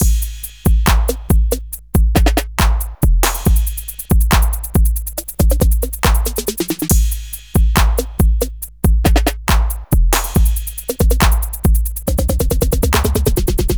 Exodus - Beat 01.wav